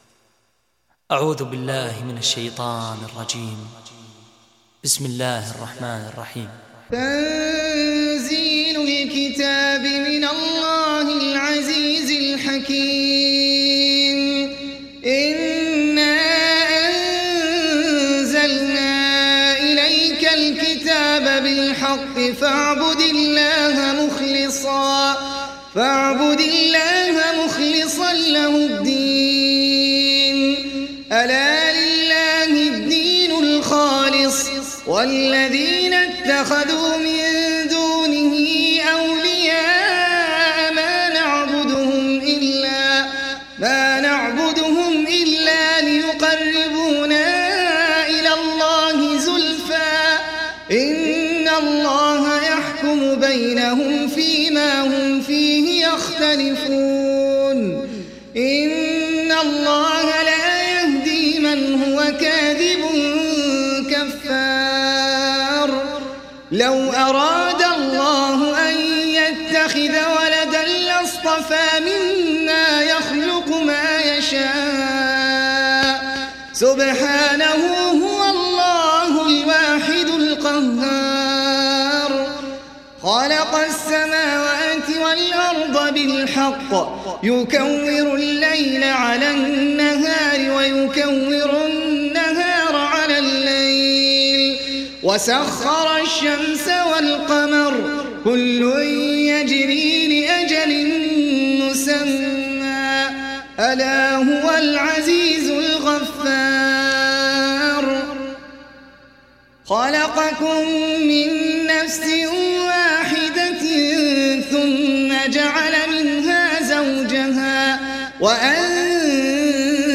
تحميل سورة الزمر mp3 بصوت أحمد العجمي برواية حفص عن عاصم, تحميل استماع القرآن الكريم على الجوال mp3 كاملا بروابط مباشرة وسريعة